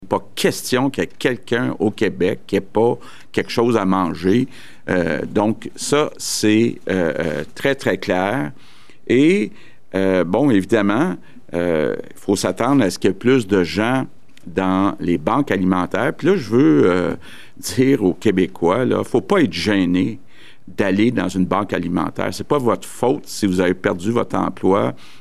Voici ce qu’avait à dire à ce sujet le premier ministre Legault :